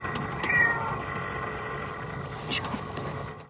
Jones speaks up and reminds her that he's still on board... "meow".